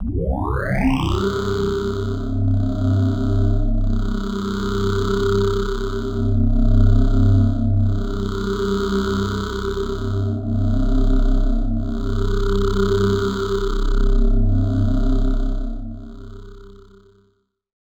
Index of /90_sSampleCDs/Club_Techno/Atmos
Atmos_06_C1.wav